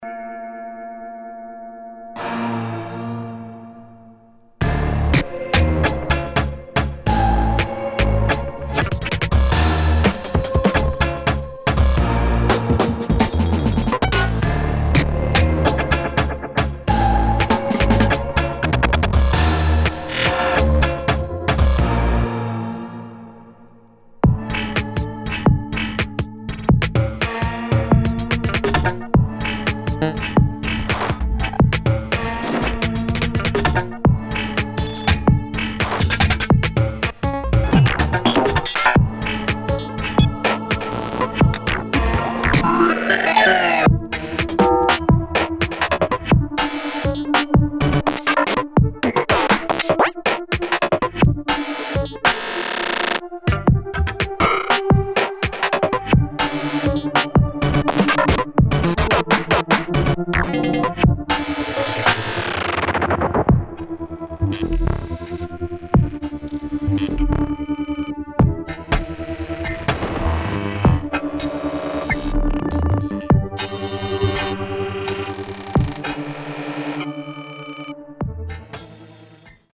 [ EXPERIMENTAL / DOWNBEAT ]